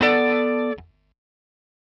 Bm7_8b.wav